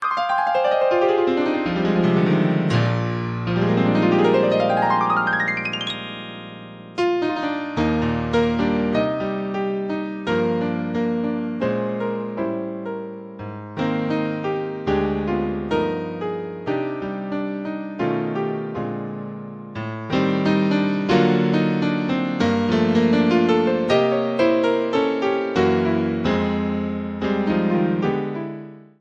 Piano Ringtones